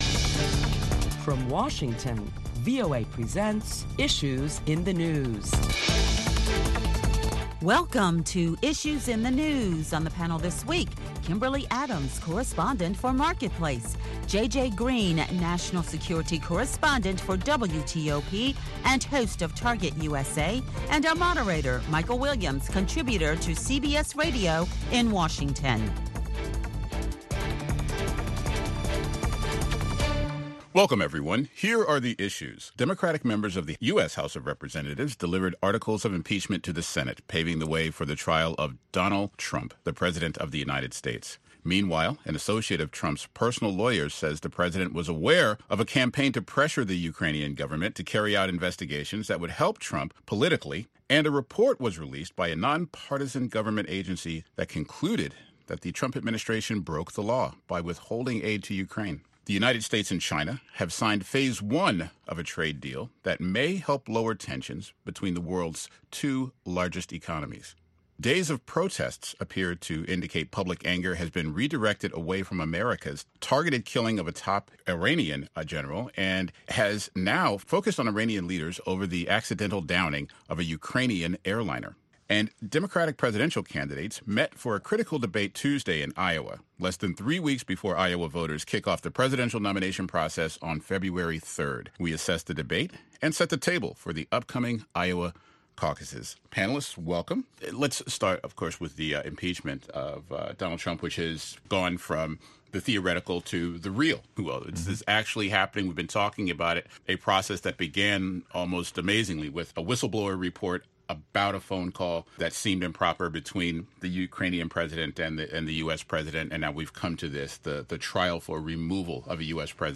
Listen to a panel of prominent Washington journalists as they deliberate the latest top stories of the week that include the U.S. looking ahead to the next phase of the trade agreement with China.